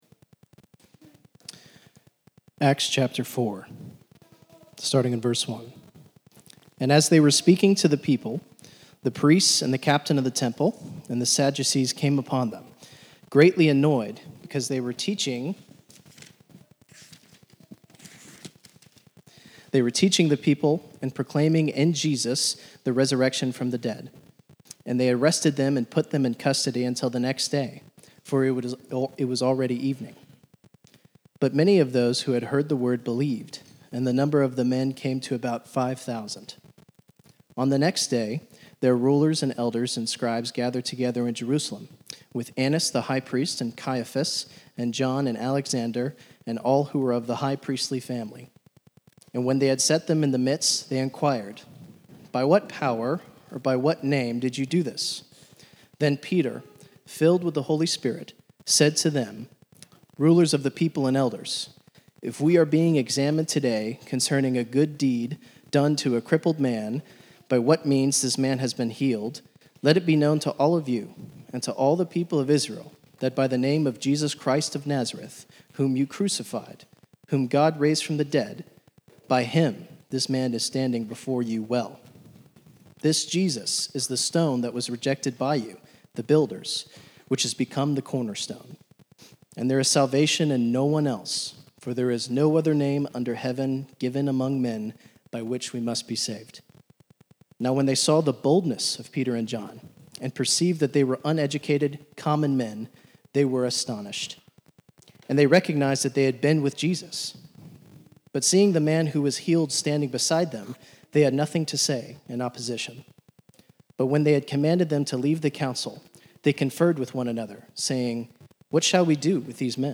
A message from the series "The Book of Acts."